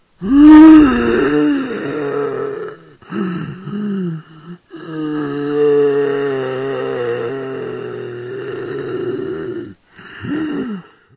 zombie_attack_8.ogg